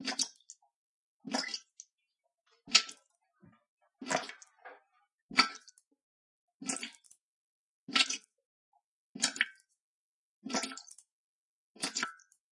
滴滴
描述：水滴